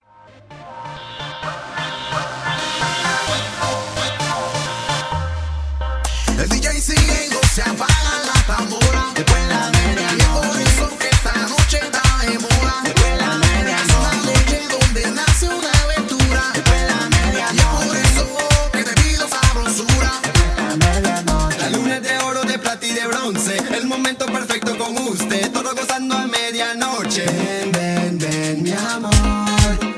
FUZION DE MERENGUE-HOUSE.